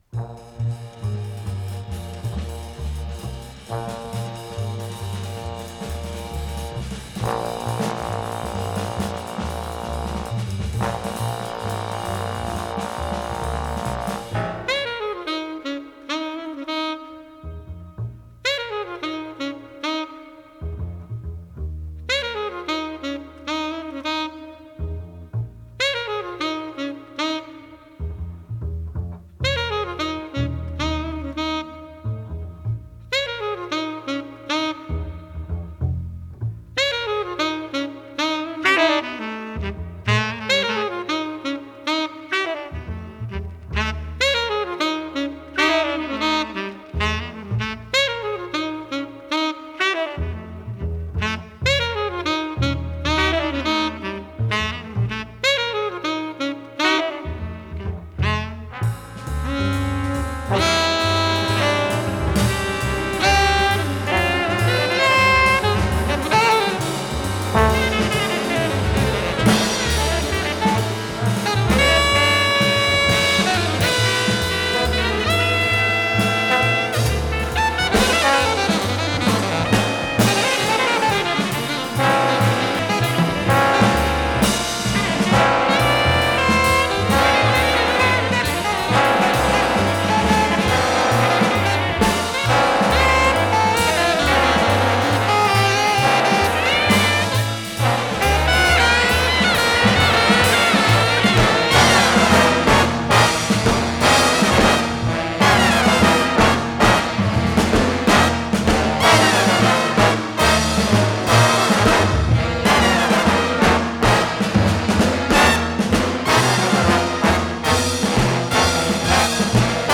с профессиональной магнитной ленты
альт-саксофон
фортепиано
ВариантДубль моно